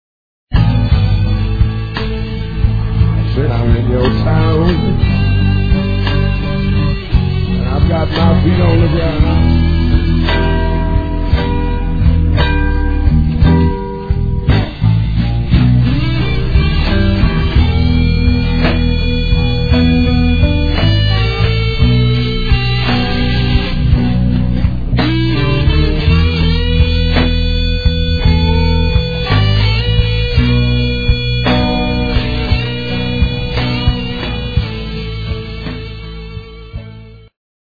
BLUES
Me (el. guitar). Live from Brno [2002].